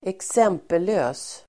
Ladda ner uttalet
Uttal: [²eks'em:pelö:s]